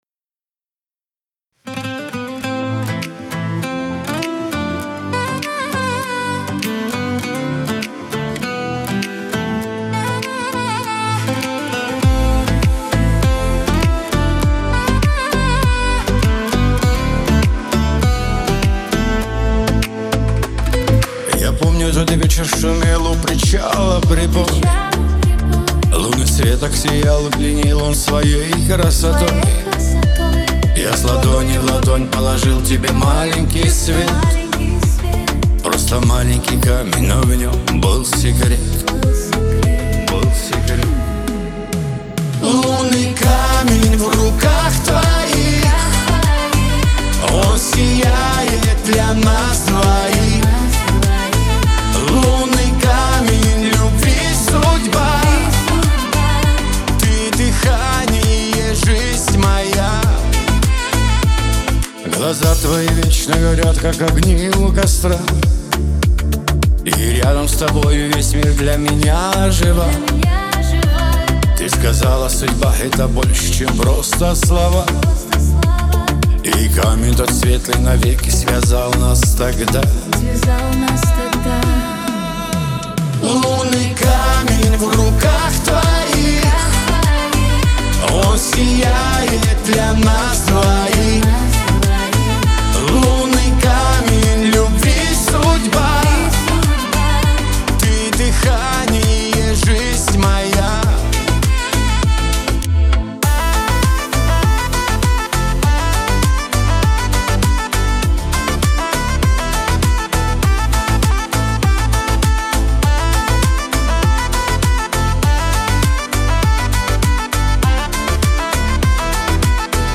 грусть , диско , эстрада